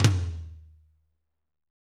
TOM M R M0LR.wav